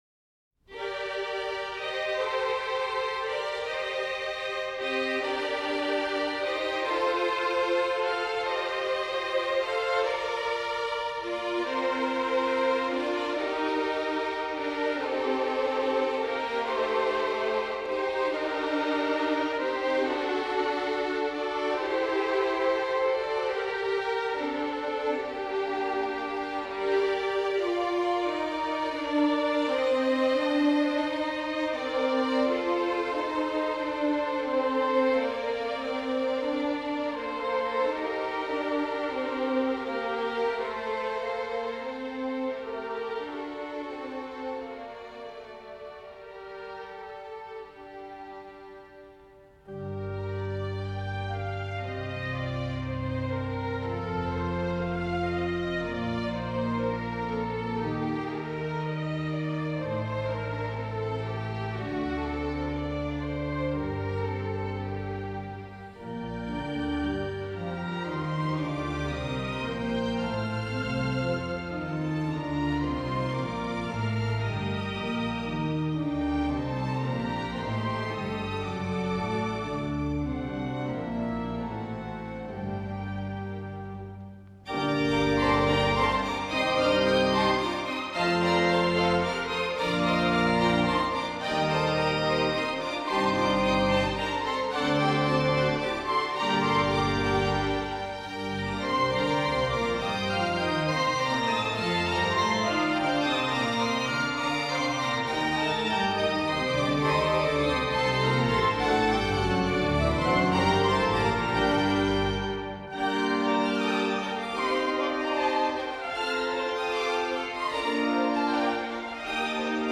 13-Гарри Гродберг (орган), анс. скрипачей ГАБТ СССР - Пассакалья (Г.Ф.Гендель. Зап. 1967 г.)